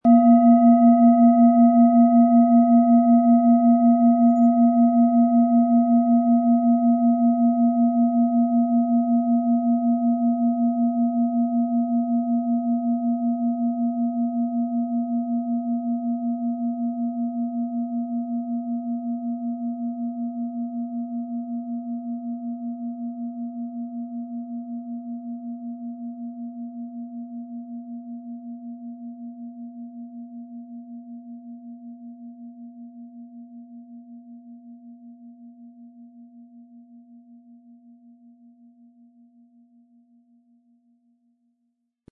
Es ist eine nach uralter Tradition von Hand getriebene Planetenton-Klangschale Chiron.
• Tiefster Ton: Mond
Mit einem sanften Anspiel "zaubern" Sie aus der Chiron mit dem beigelegten Klöppel harmonische Töne.